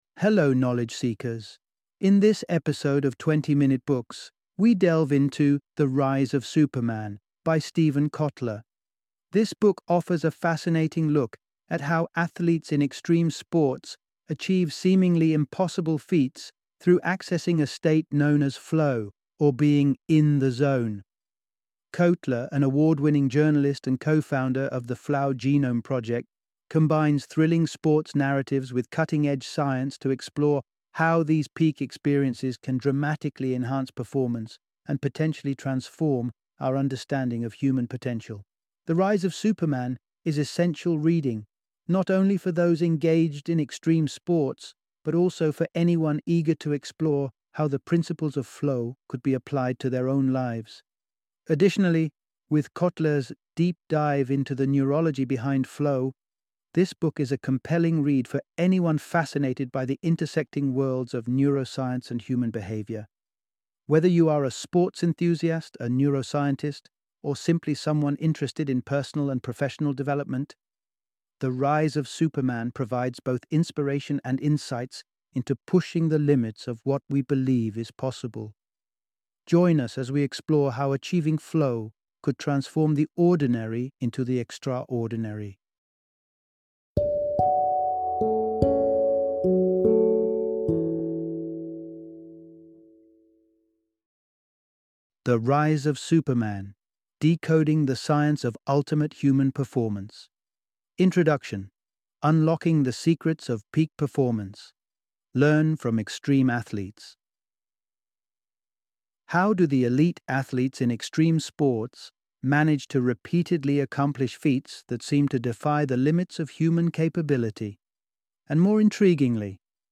The Rise of Superman - Audiobook Summary